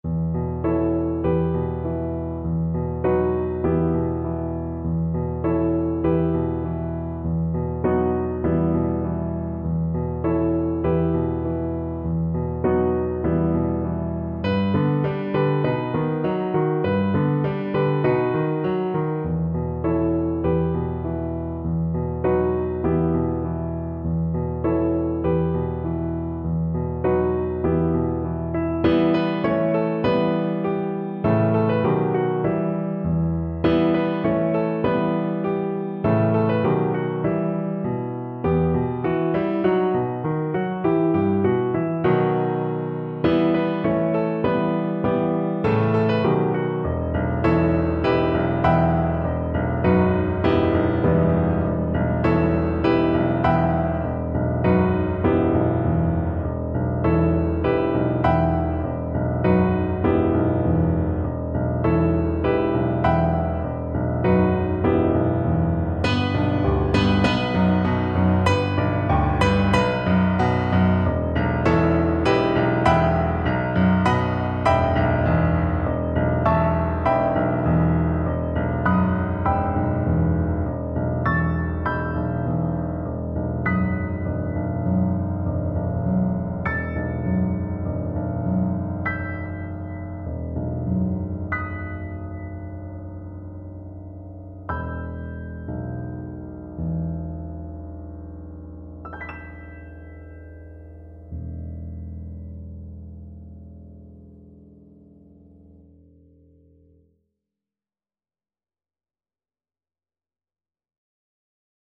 Cello
Traditional Music of unknown author.
2/4 (View more 2/4 Music)
Moderato
E minor (Sounding Pitch) (View more E minor Music for Cello )